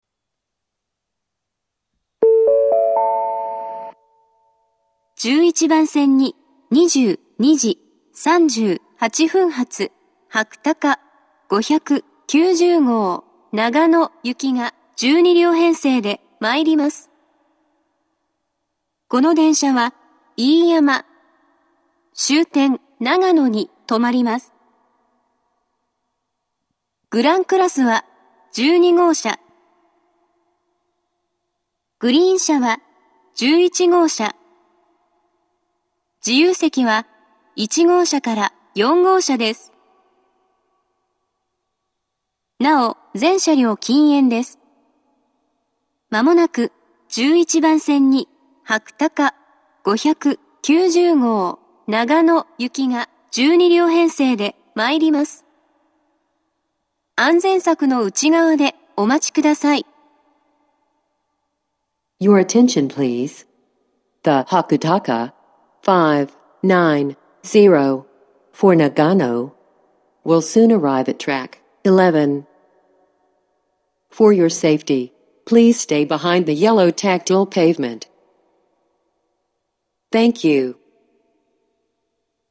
１１番線接近放送